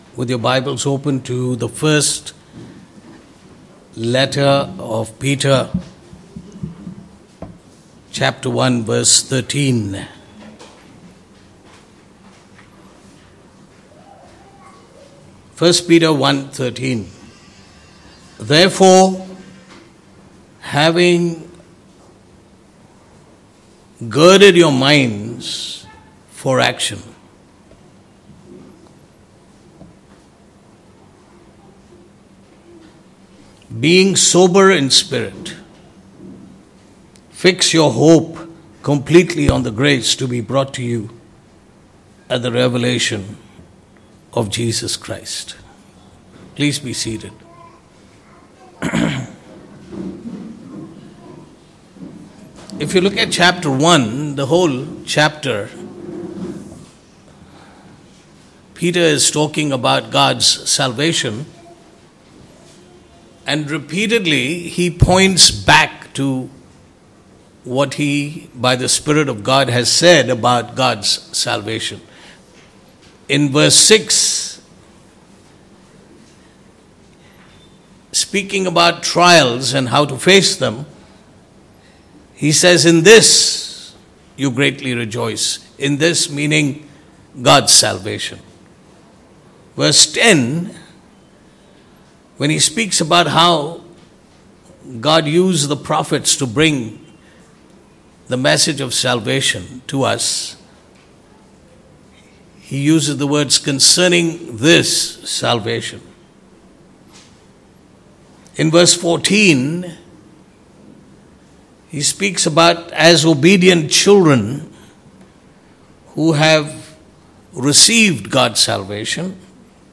Passage: 1 Peter 1:13 Service Type: Sunday Morning « How The Good News Came to Us The Reason